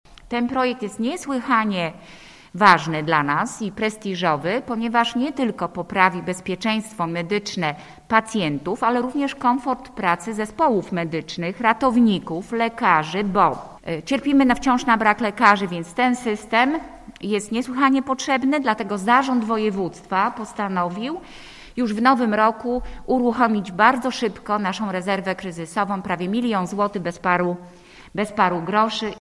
Na przeprowadzenie pilotażu nowego rozwiązania zarząd województwa przekazał blisko milion złotych. Mówi marszałek województwa Anna Polak: https